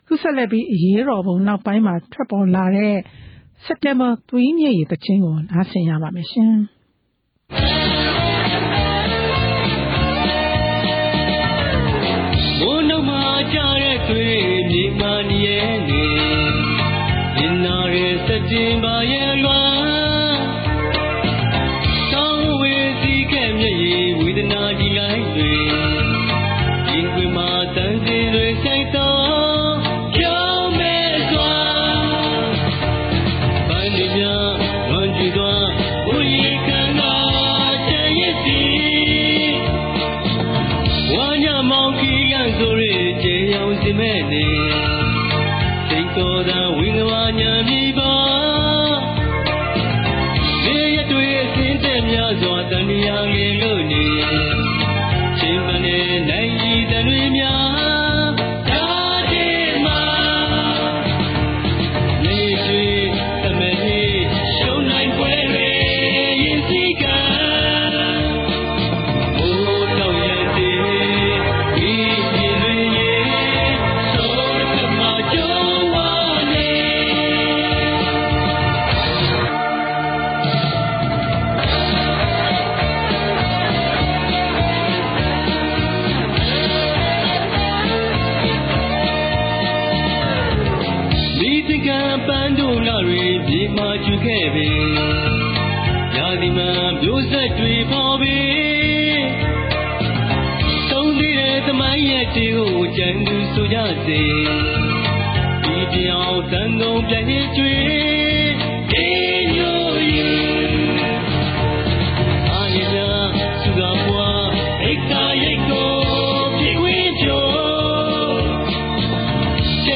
အခုဆက်လက်ပြီး အရေးတော်ပုံနောက်ပိုင်းမှာ ဒီမိုကရေစီအရေးလှုပ်ရှားသူတွေ သီဆိုခဲ့တဲ့ စက်တင်ဘာသွေးမျက်ရည် သီချင်းကို နားဆင်ရမှာဖြစ်ပါတယ်။